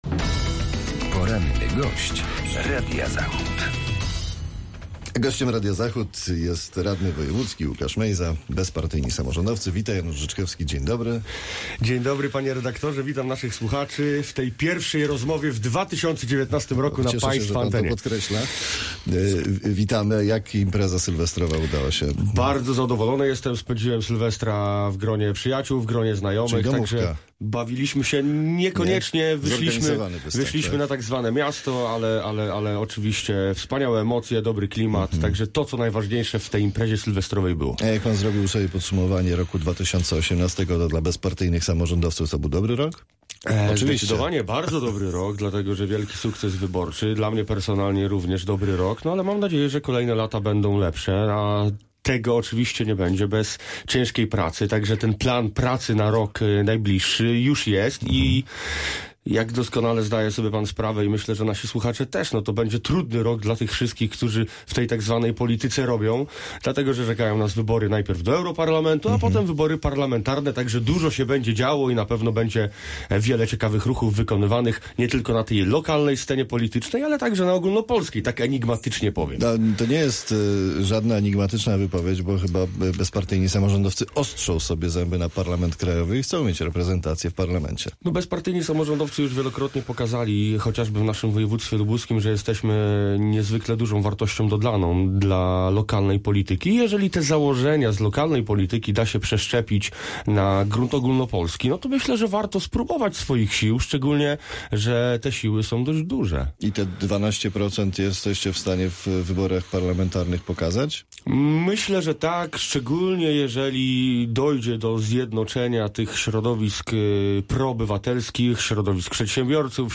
Z liderem sejmikowego klubu Bezpartyjnych Samorządowców rozmawia